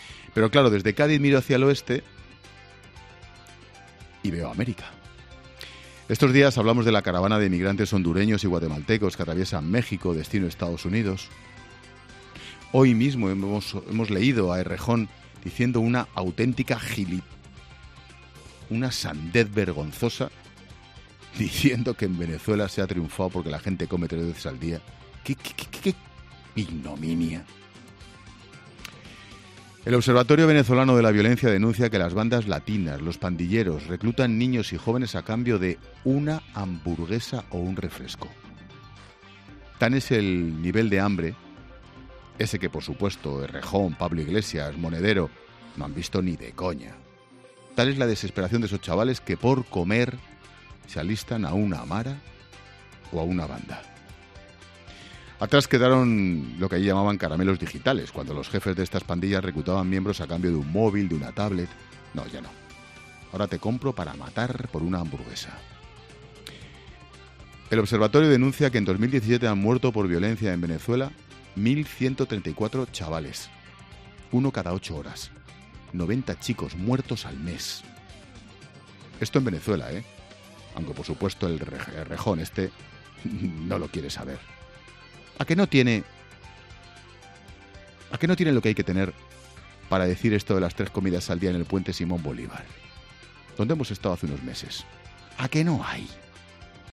"Hoy mismo hemos leído a Errejón diciendo una auténtica gili..., una sandez vergonzosa, diciendo que en Venezuela se ha triunfado porque la gente come tres veces al día. ¡Qué ignominia!", exponía Ángel Expósito en su monólogo de este martes en referencia a una entrevista concedida por el candidato de Podemos a la Comunidad de Madrid, Íñigo Errejón, a una publicación chilena en el mes de octubre en la que afirmaba, entre otras cosas, que en Venezuela "ha habido importantísimos avances" y que la gente "hace tres comidas al día".